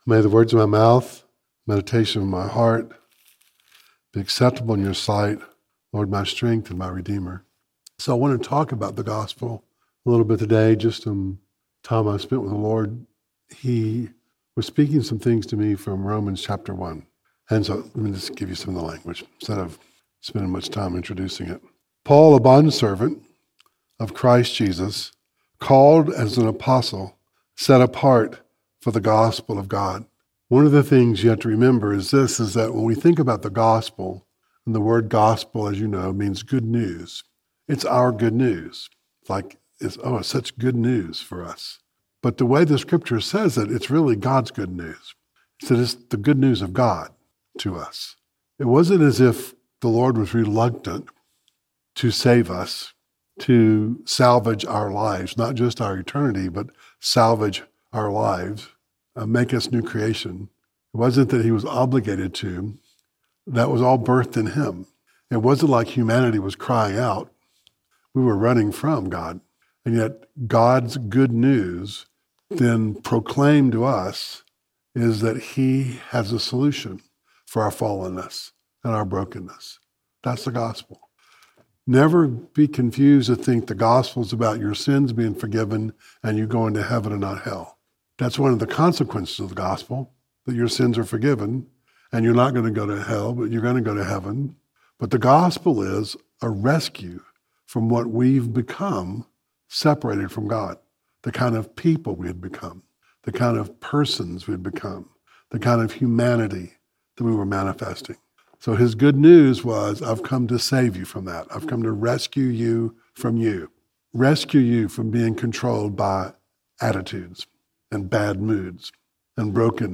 Rmans 1:1-9 Service Type: Devotional Share this